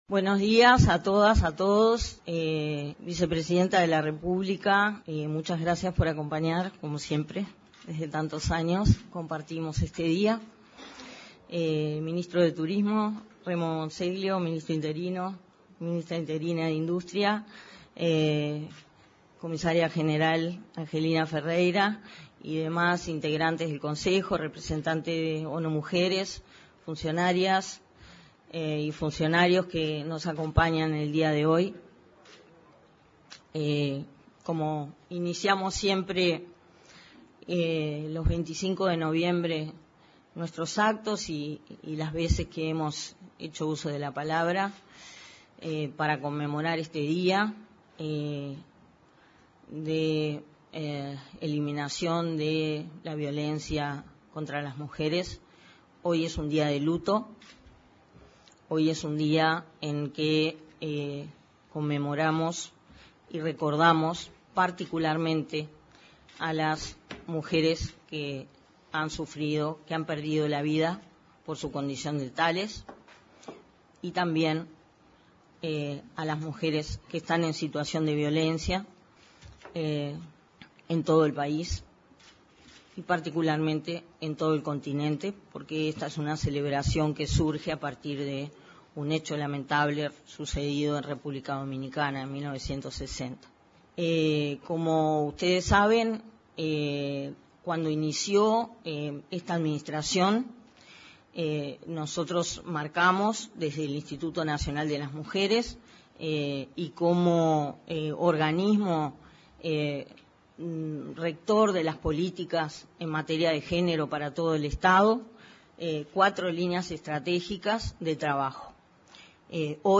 Este lunes 25, se conmemoró, en la sala de conferencias de la Torre Ejecutiva, el Dia Internacional de Eliminación de la Violencia hacia las Mujeres.